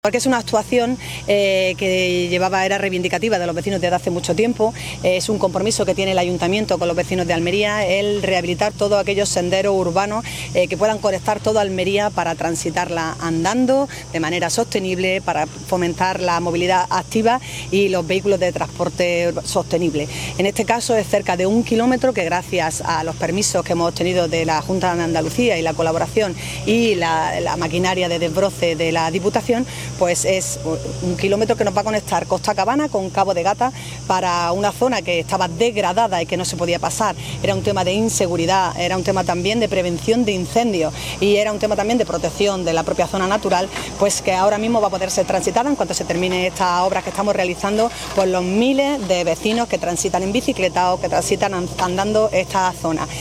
ALCALDESA-DESBROCE-SENDERO.mp3